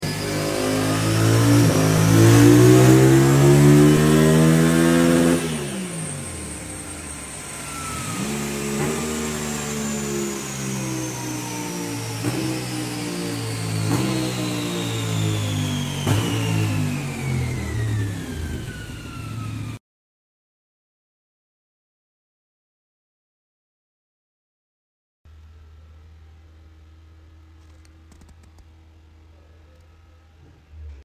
2025 Yamaha MT07 35KW and full power tuning developments✅ Ride modes Unlock! We have been working on these new MT07 and sure they have lots of potential👌🏼 With a full exhaust system and a good air filter we can push them just above 80+ Horspower!